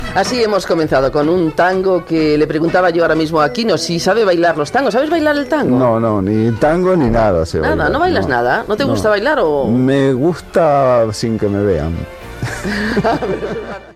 Fragment d'una entrevista al dibuixant argentí Quino (Joaquín Salvador Lavado Tejón)